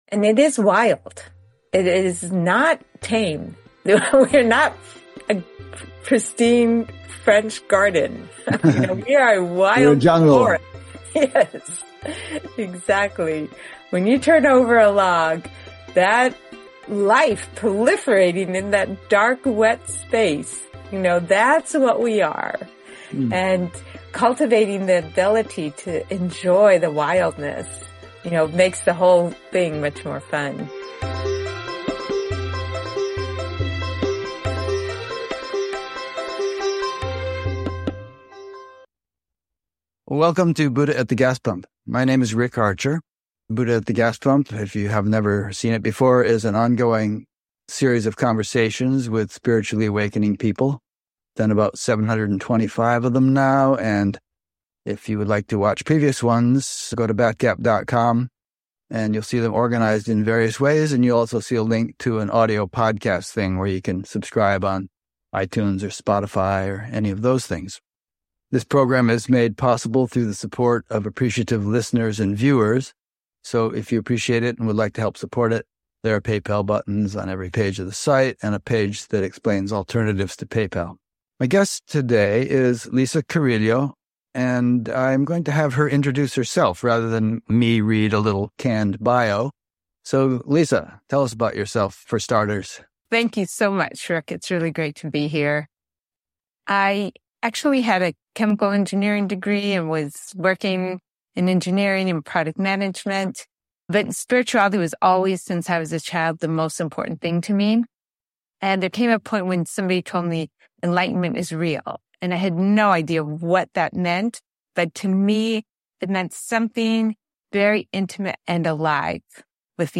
Interview recorded January 26, 2025